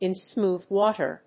アクセントin smóoth wáter(s)